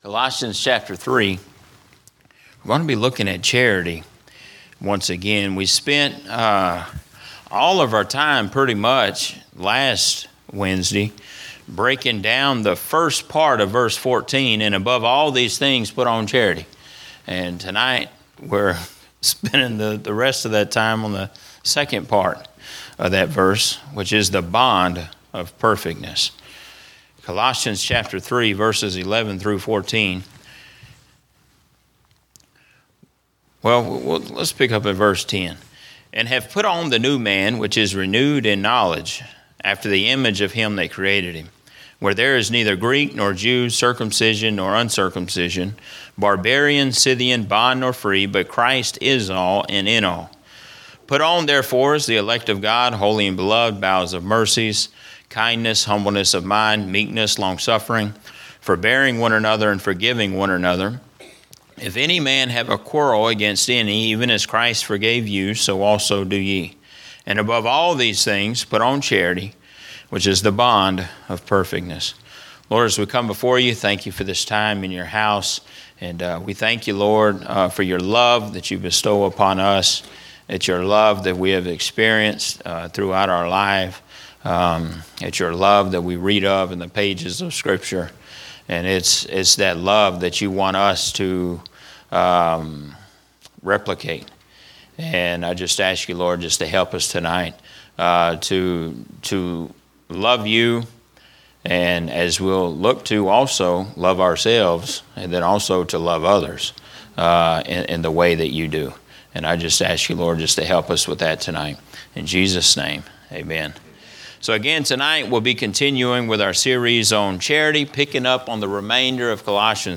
A message from the series "General Preaching."
Preaching